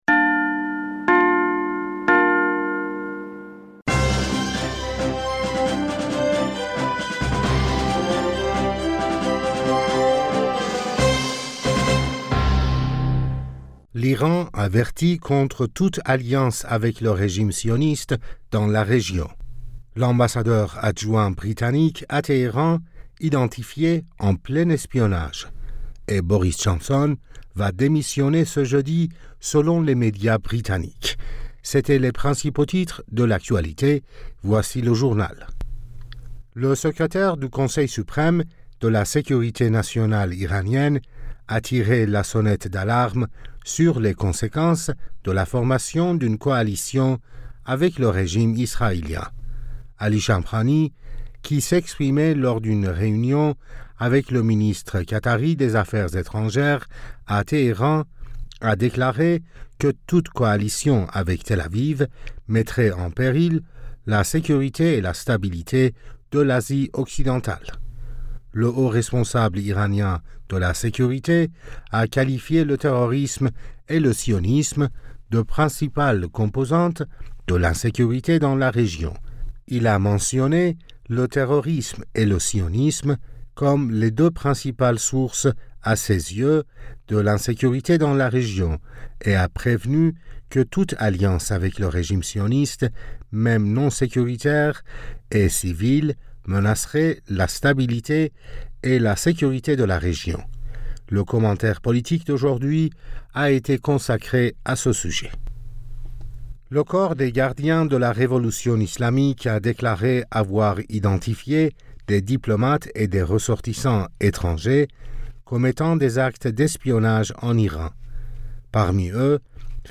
Bulletin d'information Du 07 Julliet